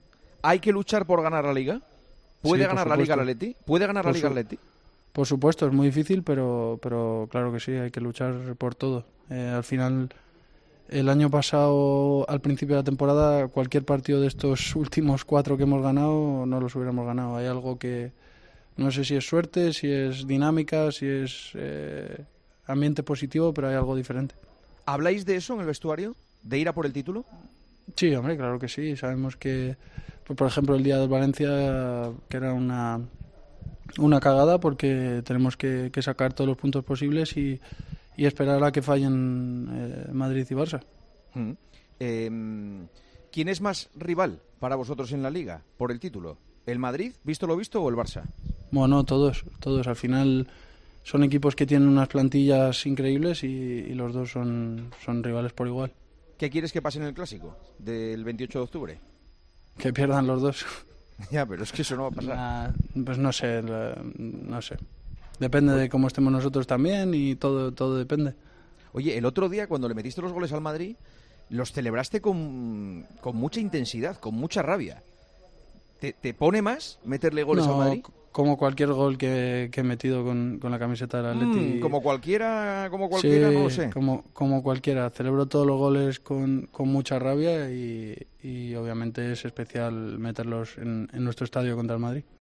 AUDIO: Juanma Castaño le preguntó al delantero del Atlético de Madrid si los rojiblancos sueñan con ganar la Liga esta temporada.